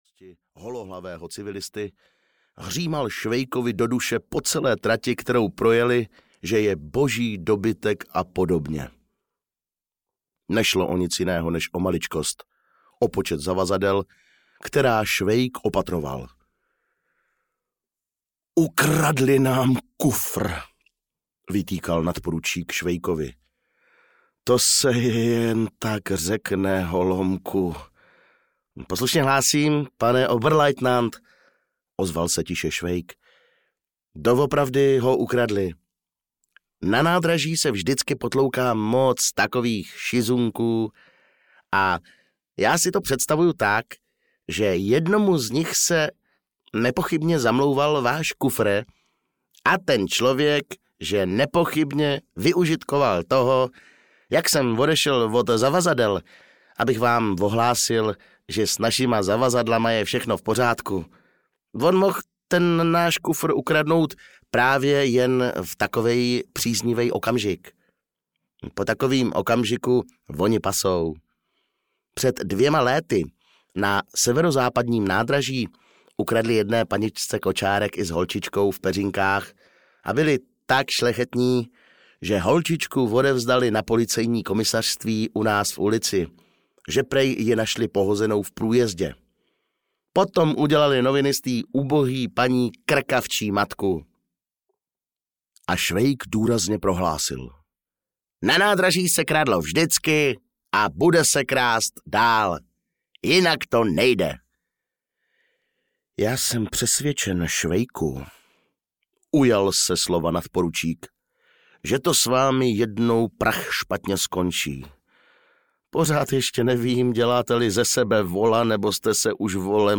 Osudy dobrého vojáka Švejka – Na frontě (2. díl) audiokniha
Ukázka z knihy